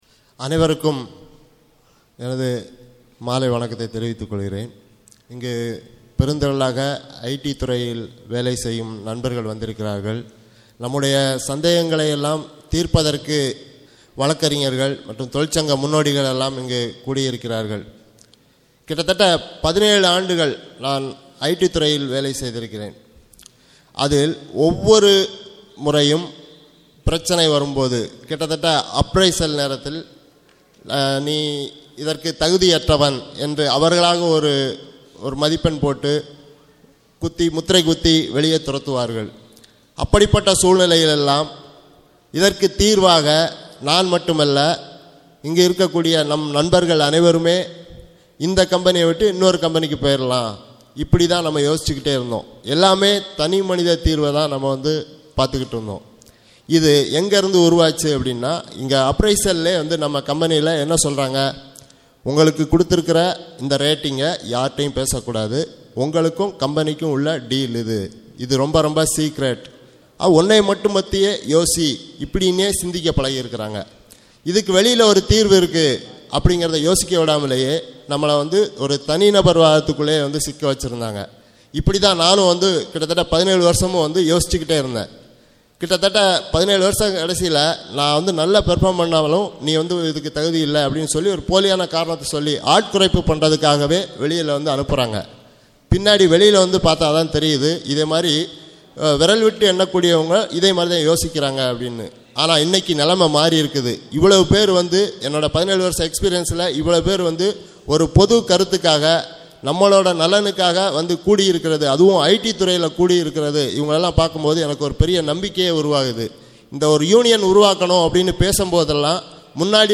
ஐ.டி துறை யூனியன் – கலந்துரையாடல் வீடியோக்கள்